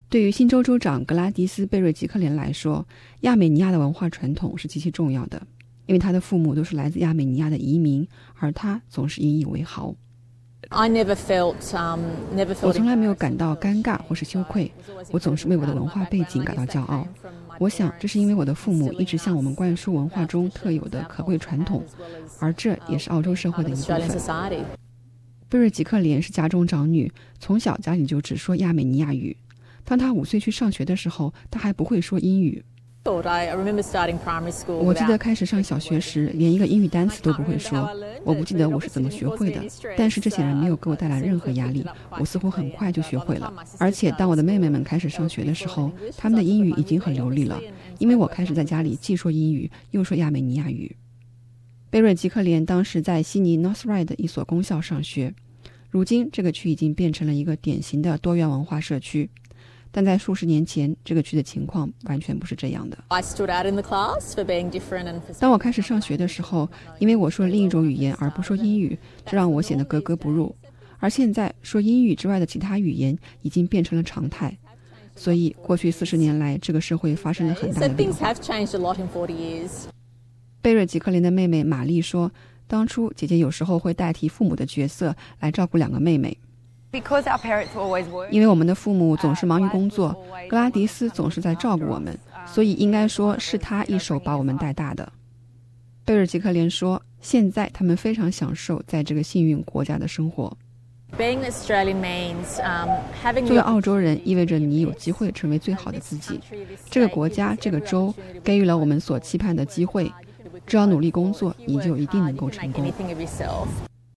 去年一月，贝瑞吉克莲当选为新州州长，成为澳洲政坛少有的女性州长之一。贝瑞吉克莲在采访中说为自己的亚美尼亚传统感到非常自豪。